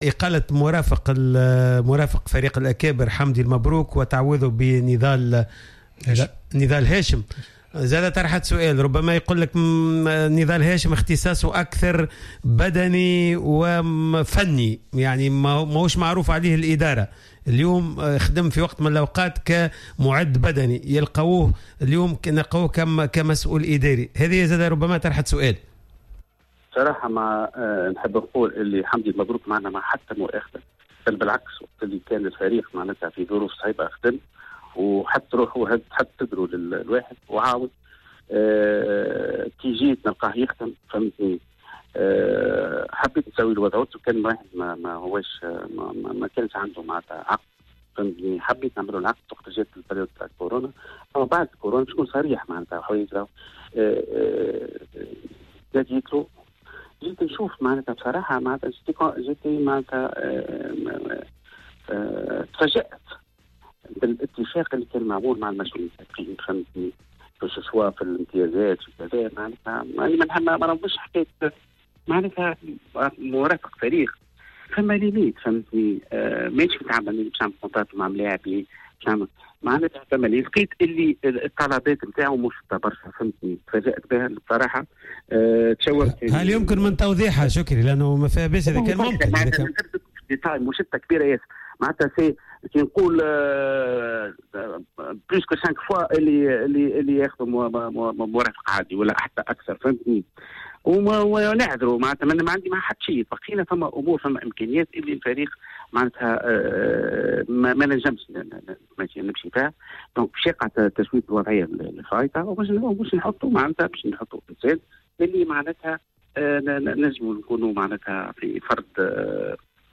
مداخلة في حصة "راديو سبور"